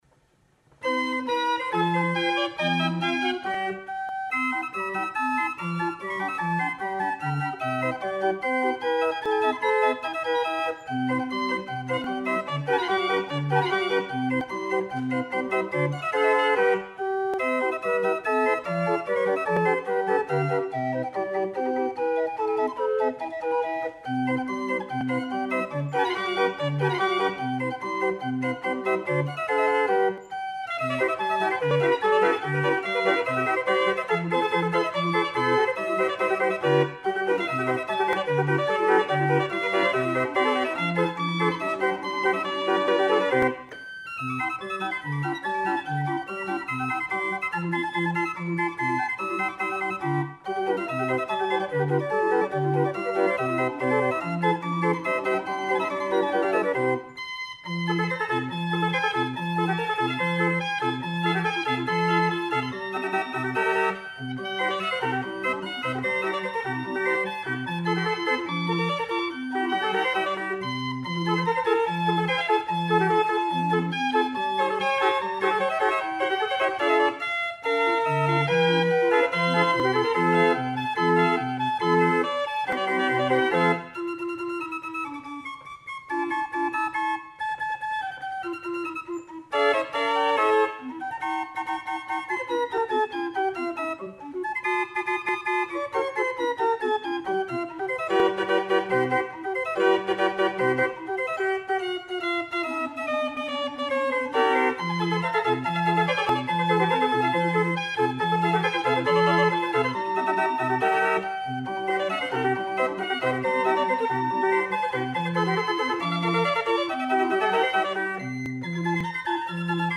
32-note street organ with 77 pipes and 3 stops : tin flutes, wooden bourdons, trumpets.
The wooden trumpets provide a powerful yet mellow tone.